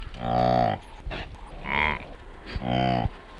На этой странице собраны разнообразные звуки сайги – от голосовых сигналов до шумов, которые издают эти степные антилопы.
Звук сайги пытающегося мычать маргач